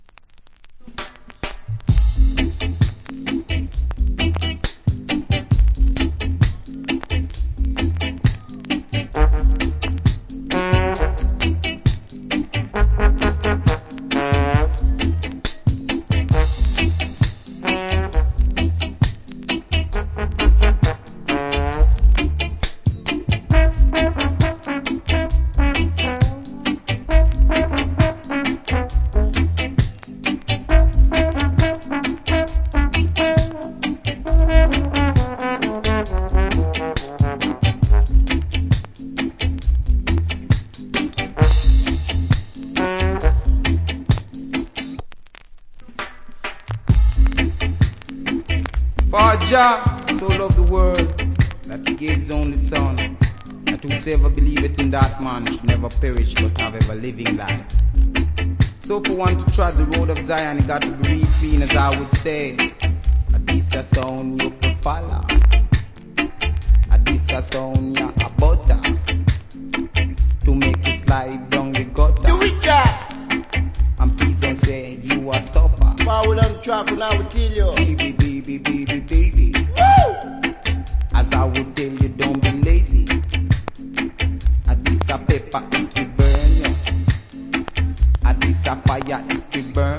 Wicked Horn.